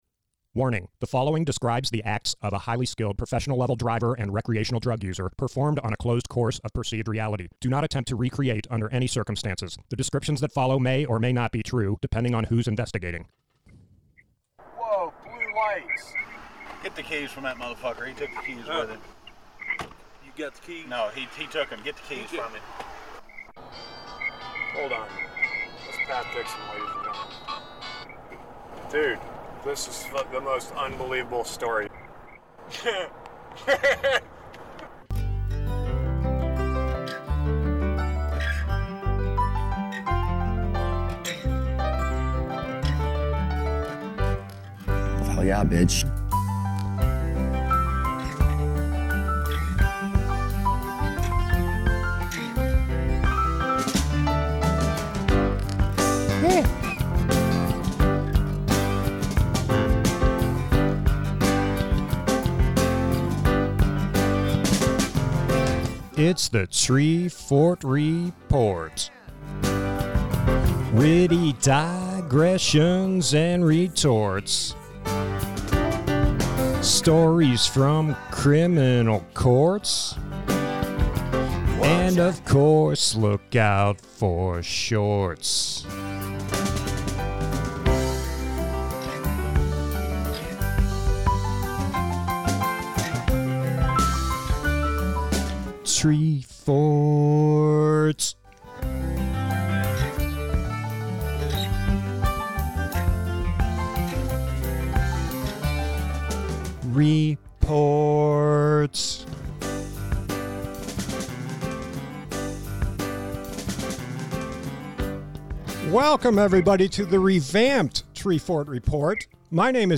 Play Rate Listened List Bookmark Get this podcast via API From The Podcast Streetwise humor fills our lighthearted discussion of prison stories, criminal anecdotes, and whatever else happens to surface.